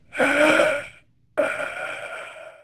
monster.ogg